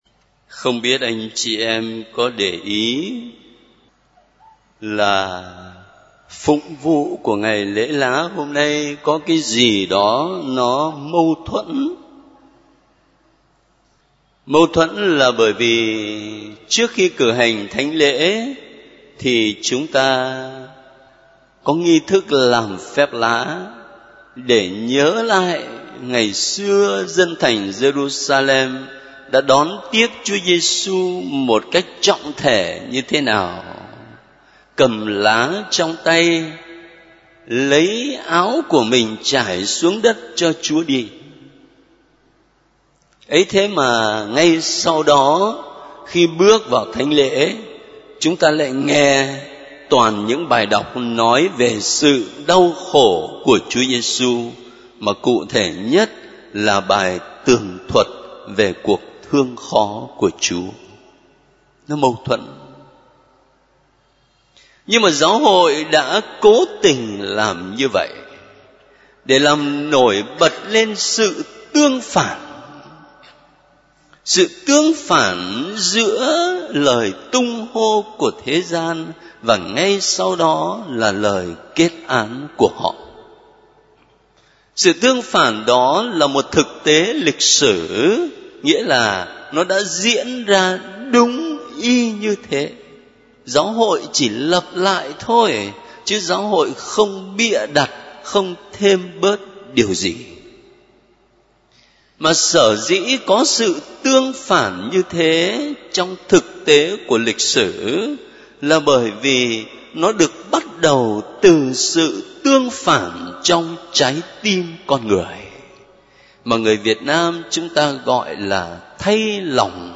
Ca sĩ: Gm. Phêrô Nguyễn Văn Khảm
Dòng nhạc : Nghe giảng